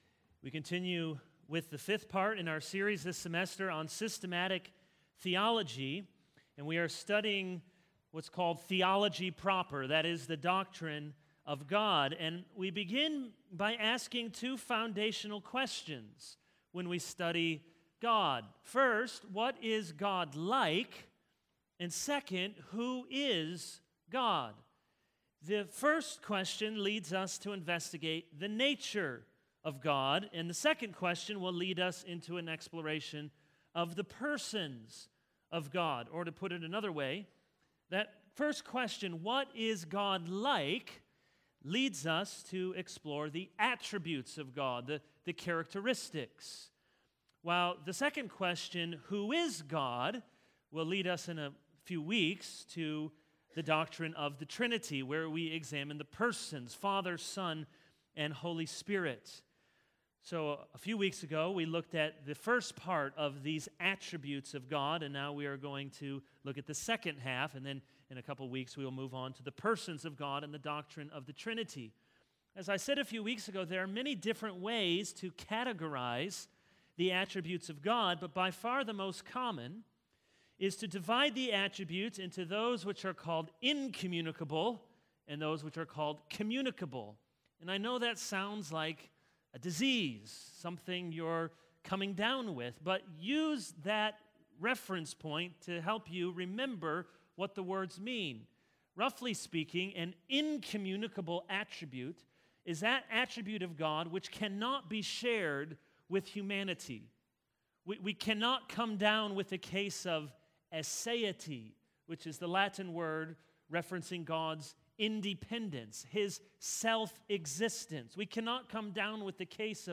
All Sermons The Communicable Attributes of God 0:00 / Download Copied!